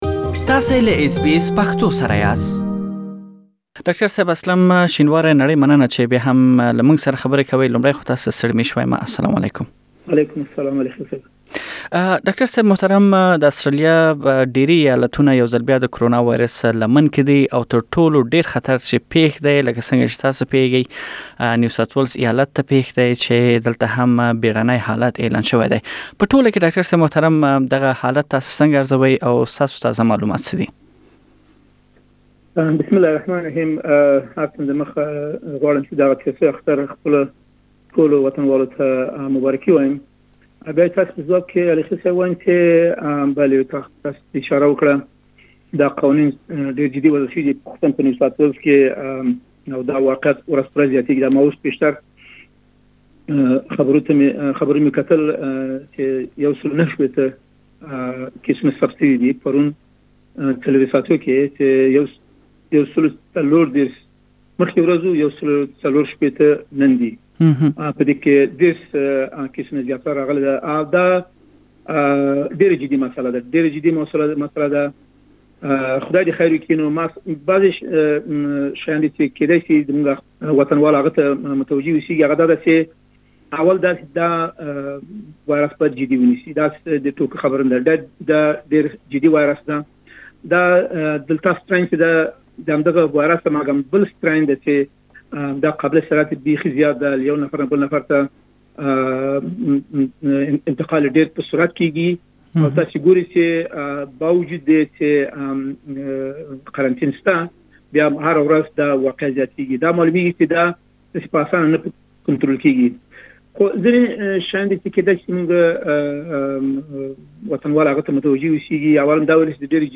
تاسو ته مو په اسټراليا کې او بيا په ځانګړي ډول سيدني ښار کې د کرونا ويروس حالت راخيستی او داچې افغانان او پښتانه ياد ويروس پر وړاندې څنګه عمل کوي، دا ټول پدې بشپړې مرکه کې واورئ.